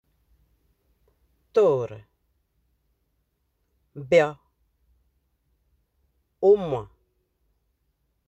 Accueil > Prononciation > o > o